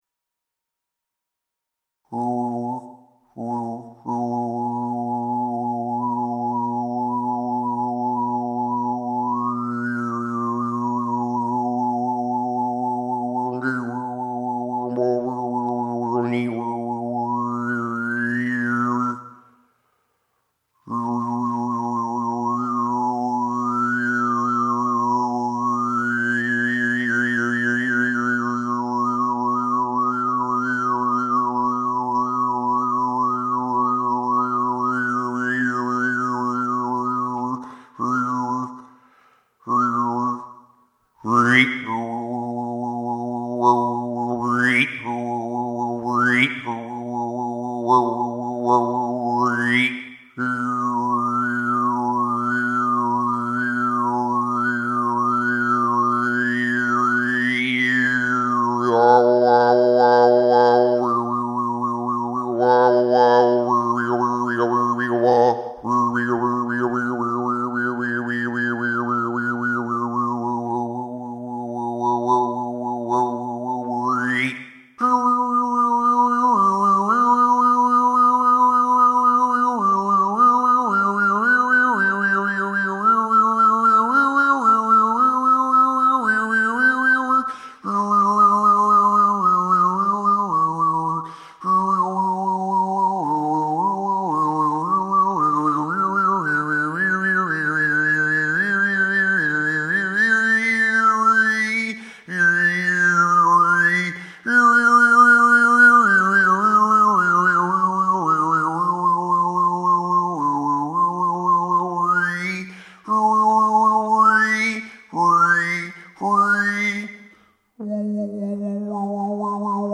7. Borbangnadyr (excerpt) – Borbangnadyr is a Tuvan throat singing technique with which a singer responds vocally to the sound of a moving stream. My composition does not employ the traditional technique but it retains the same connection and response to nature.